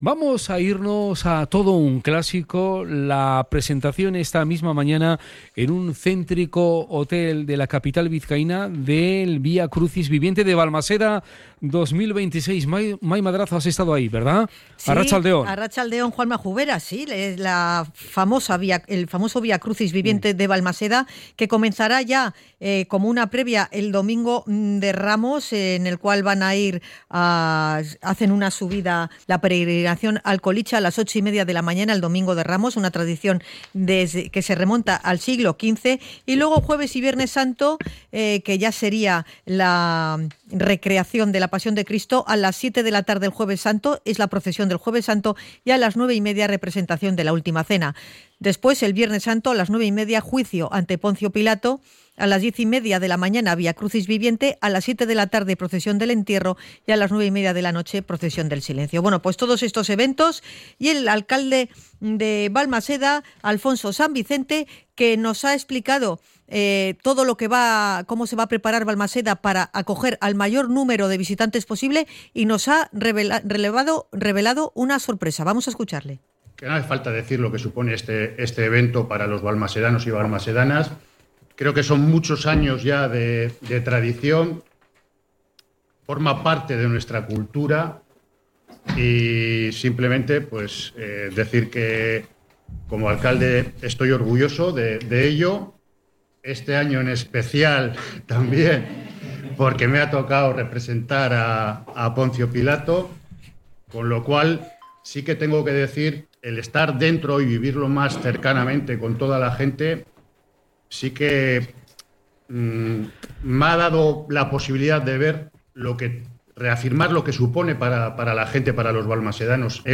Crónica de la presentación de la Pasión Viviente de Balmaseda
El alcalde de Balmaseda, Alfonso San Vicente, ha subrayado el valor cultural y emocional que tiene esta cita para el municipio.